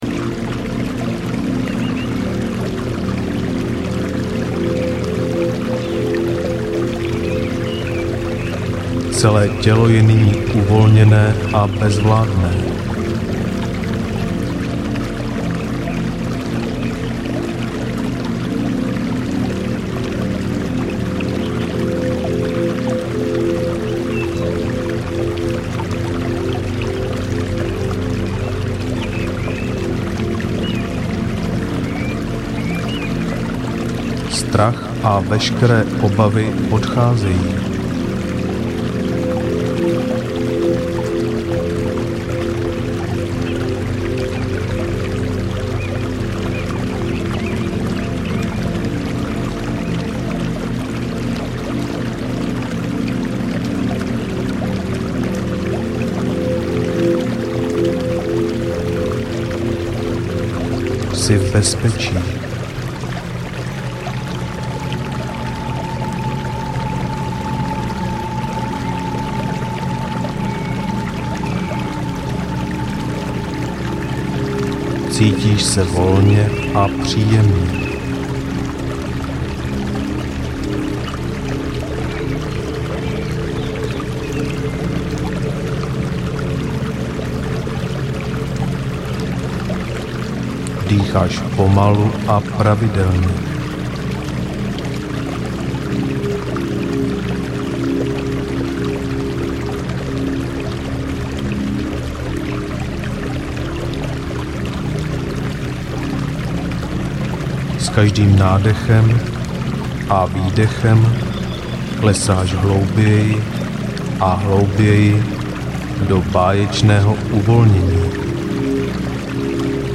Hluboká relaxace audiokniha
Ukázka z knihy